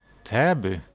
Täby (Swedish pronunciation: [ˈtɛ̌ːbʏ]
Sv-Täby.ogg.mp3